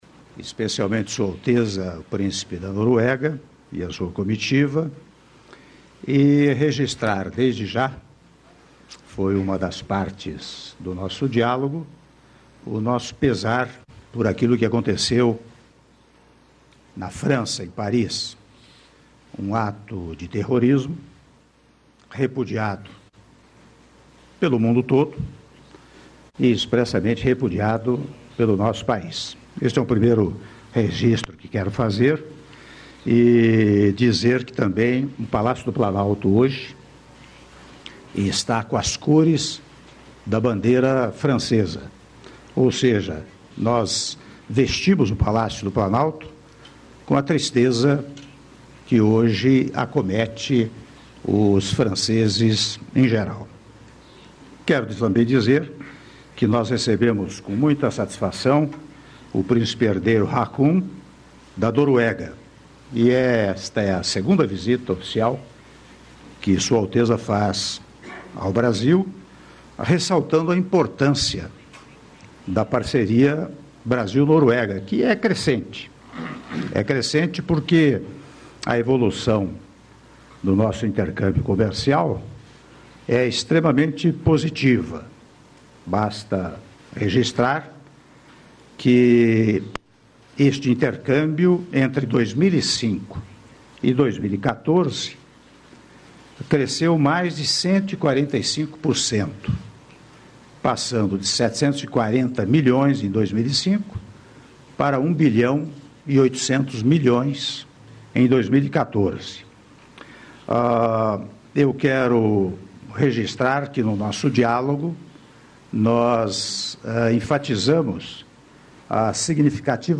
Áudio da declaração à imprensa do Presidente da República em exercício, Michel Temer, após encontro com o Príncipe Herdeiro da Noruega, Haakon Magnus - Brasília/DF (5min10s)